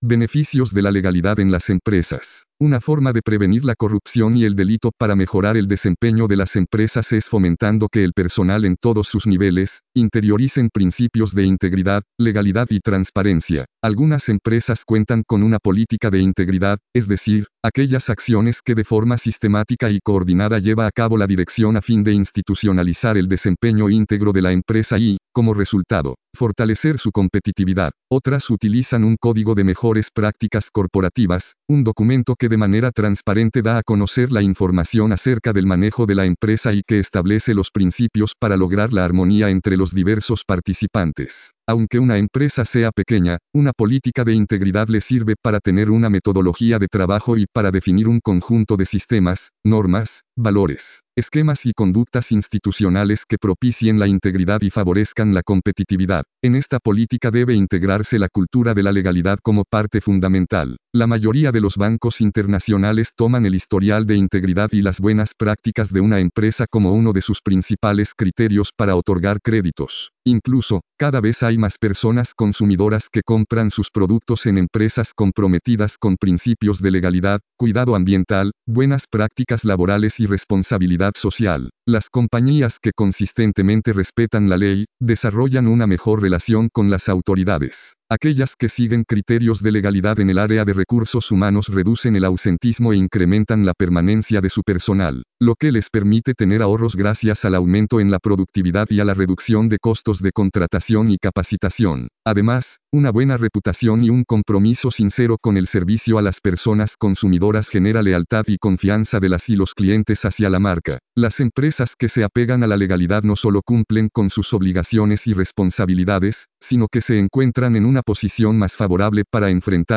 Lectura-Beneficios-de-la-legalidad-en-las-empresas.mp3